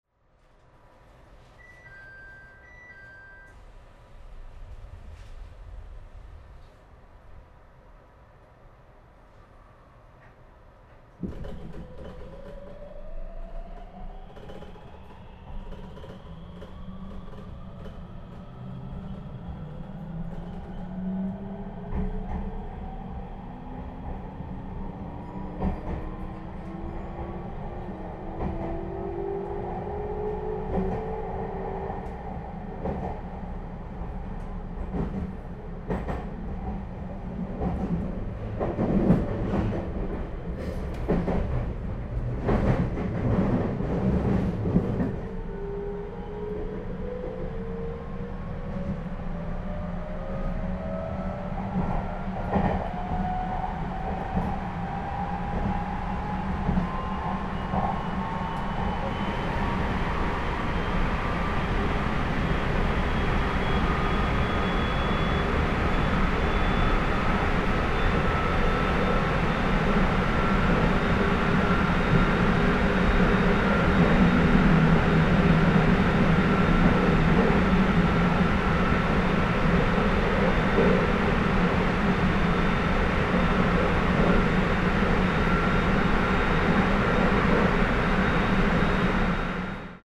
録音車両：HK100-101
今回のCDは、この快速「スノーラビット」を越後湯沢→直江津間で録音しました。トンネルが続くほくほく線内を、最高速度を維持して走るHK100形の走行音をお楽しみください。